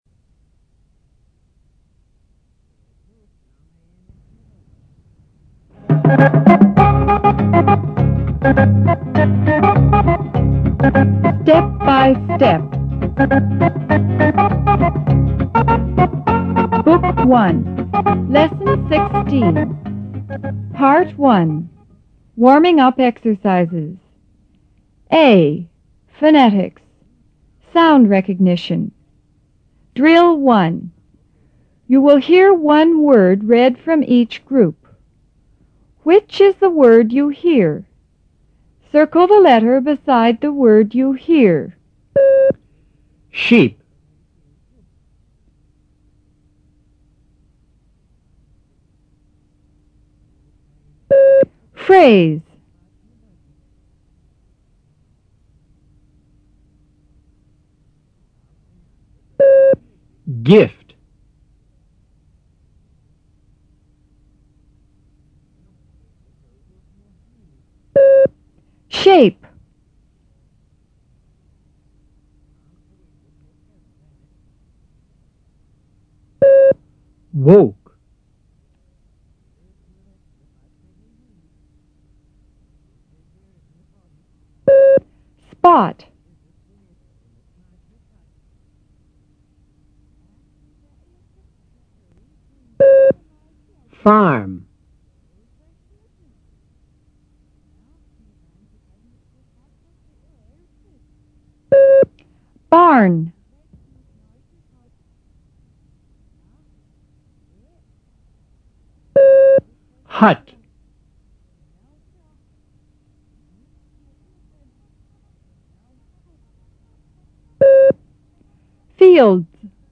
A．Phonetics: Sound Recognition
B．Sentences For Oral Repetition